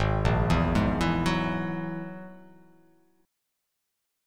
Ab7sus2#5 chord